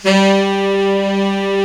Index of /90_sSampleCDs/Giga Samples Collection/Sax/HARD + SOFT
TENOR SOFT G.wav